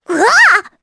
Rephy-Vox_Damage_jp_01.wav